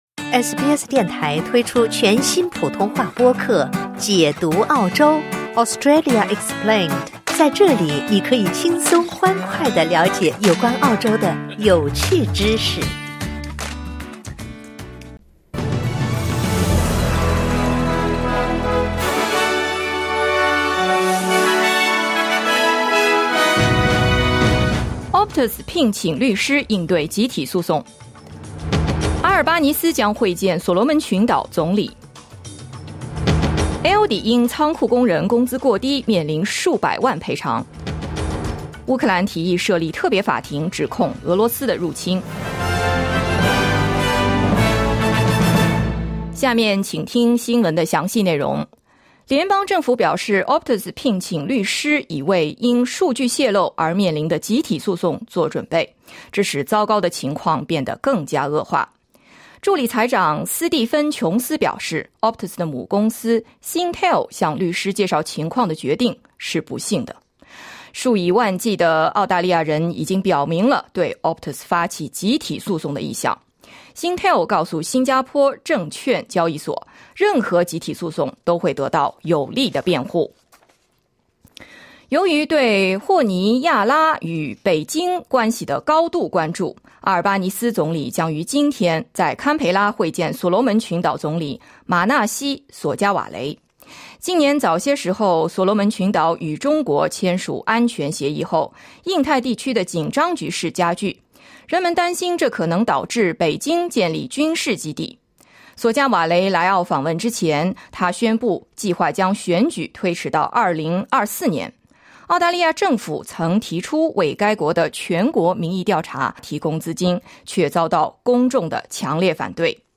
SBS早新闻 (2022年10月6日)